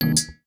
UIClick_Smooth Tone Metallic Double Hit 01.wav